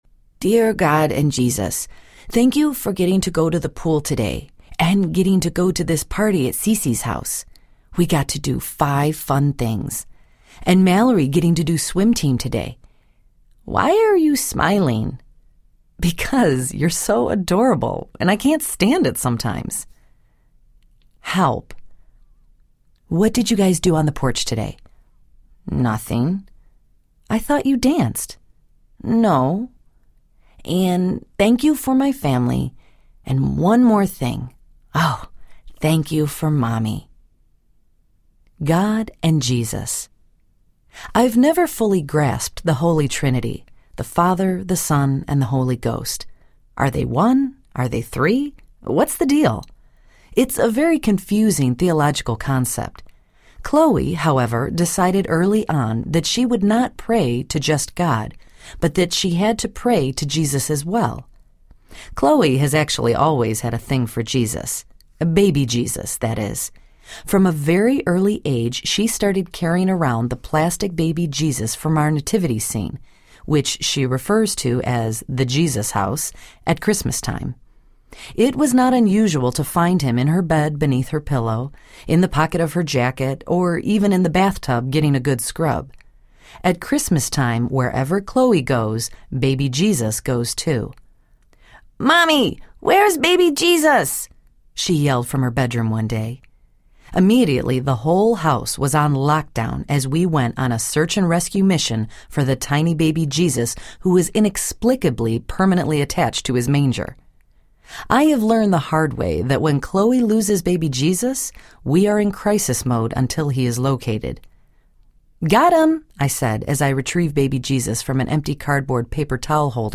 I Love You to God and Back Audiobook
Narrator
6.5 Hrs. – Unabridged